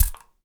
spray_bottle_02.wav